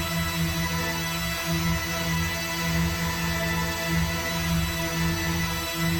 DD_LoopDrone3-E.wav